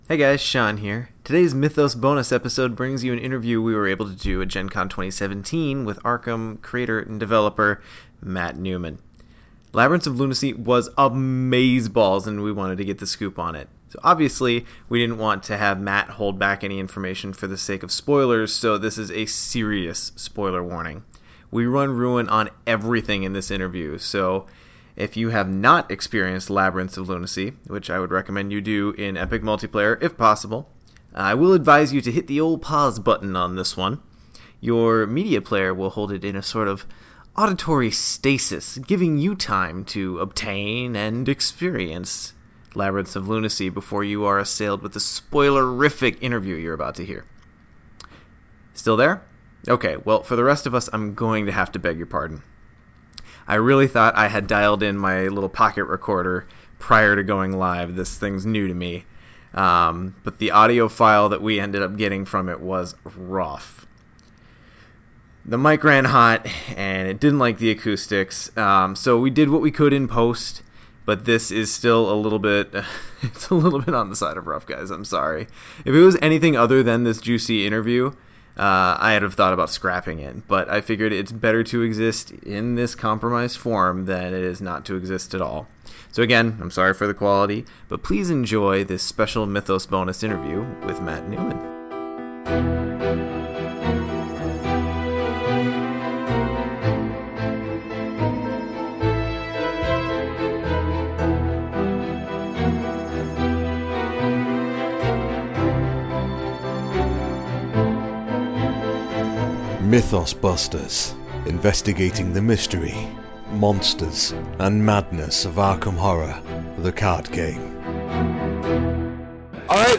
Please be patient as the recording quality is what you would expect from the middle of such a big event.